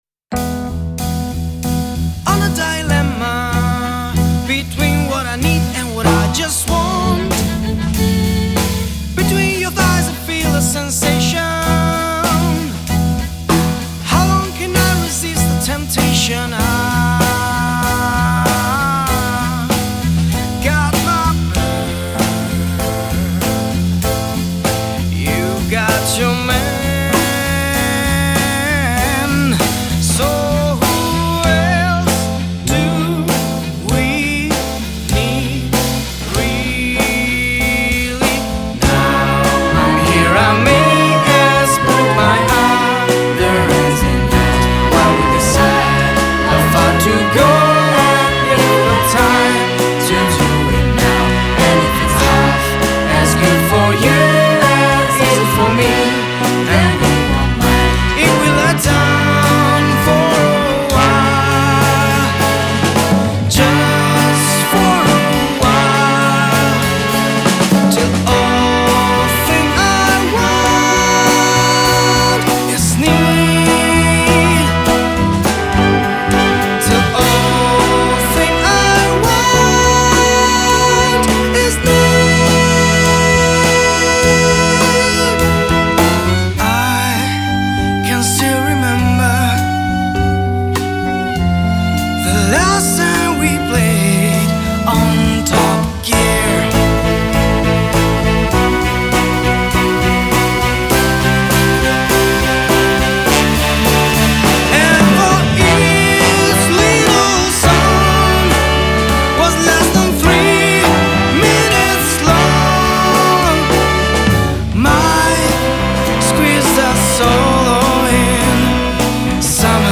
registrato a Milano, Officine Meccaniche studio A.